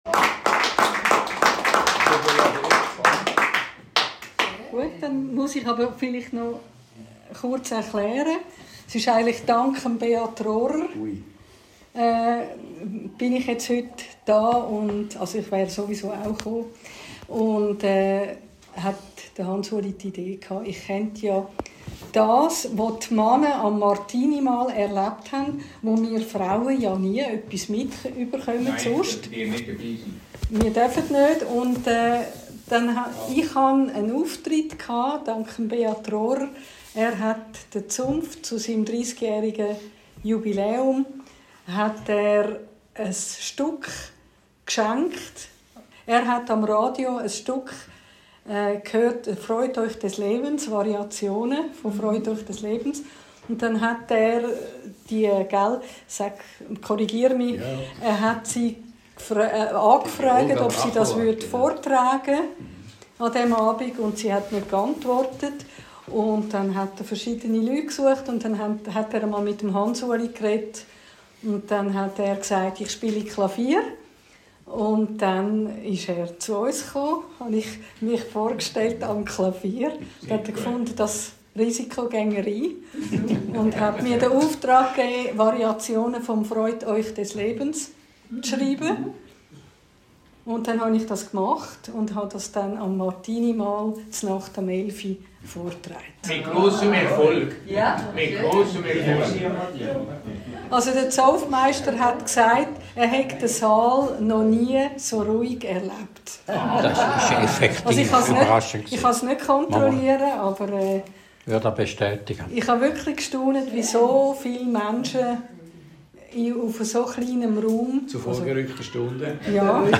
Aufnahme vom ZB-Chlaus vom 3. Dez 2025